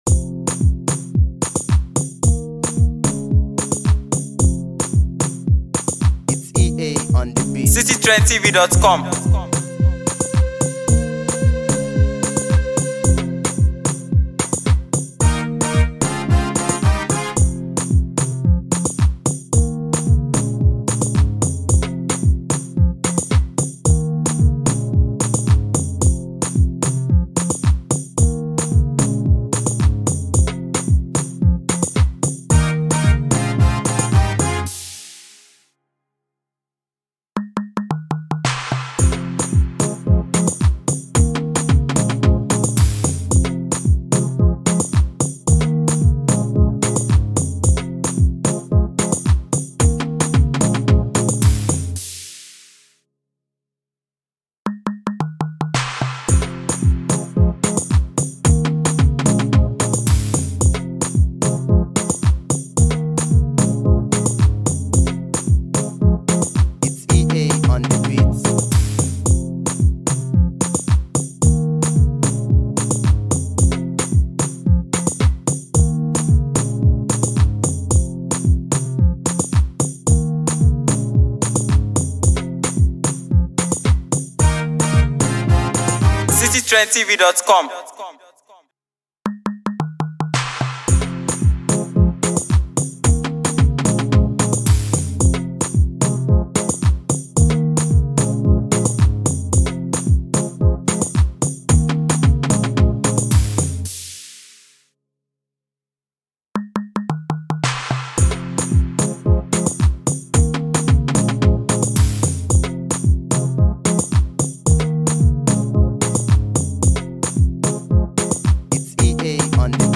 GENRE: Afro